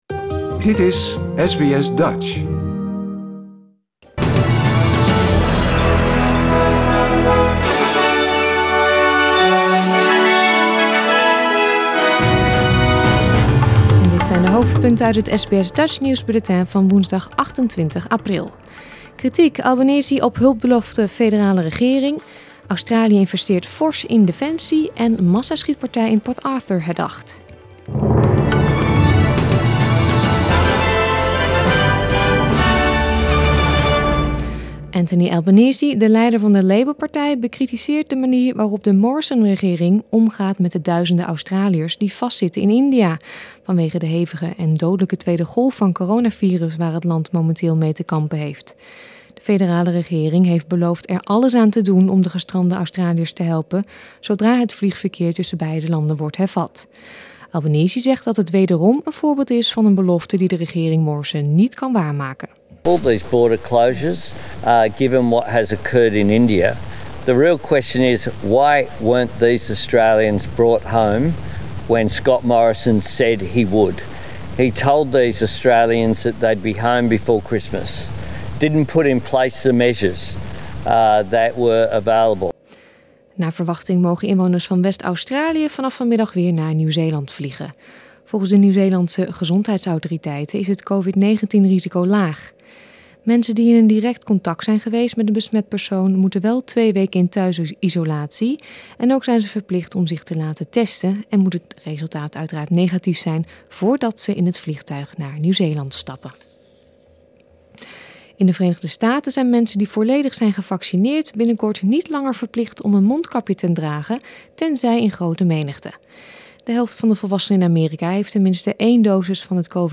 Nederlands/Australisch SBS Dutch nieuwsbulletin woensdag 28 april 2021